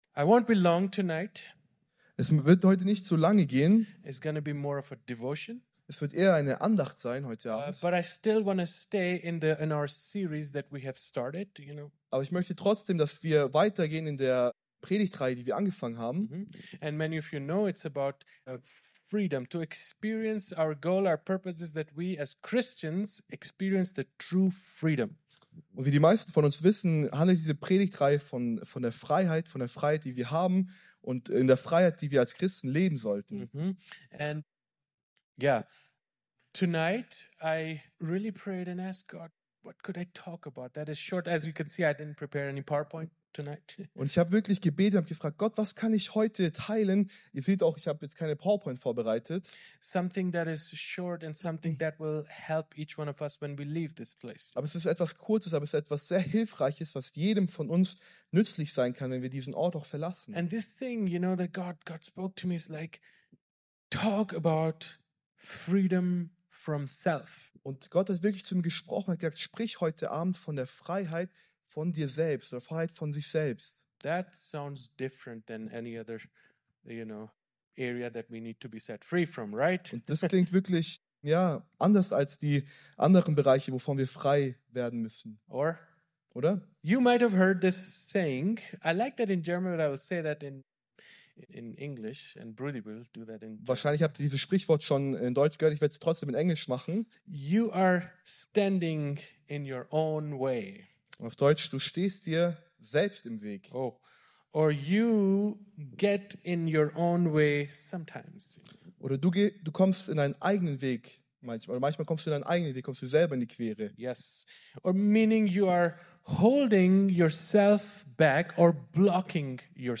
Predigt
im Christlichen Zentrum